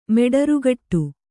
♪ meḍarugaṭṭu